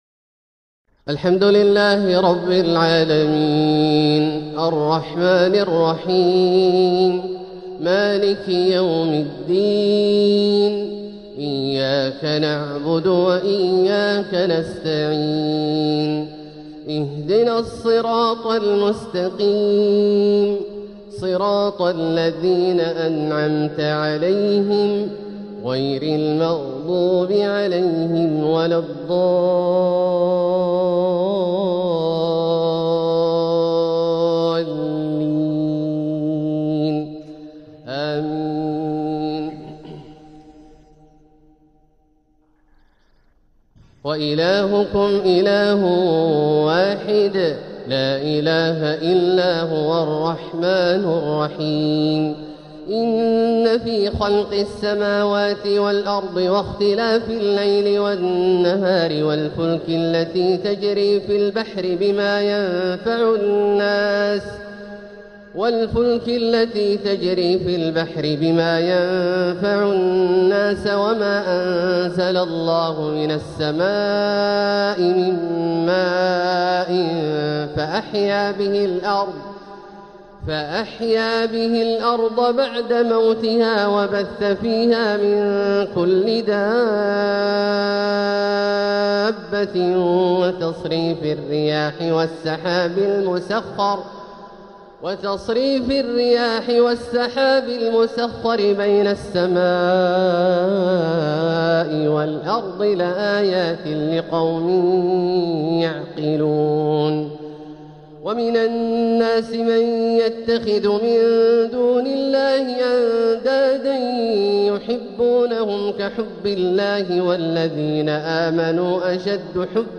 القطف الجني لتلاوات الشيخ عبدالله الجهني | شهر ربيع الأول ١٤٤٧هـ " الحلقة الثالثة والثمانون" > القطف الجني لتلاوات الجهني > المزيد - تلاوات عبدالله الجهني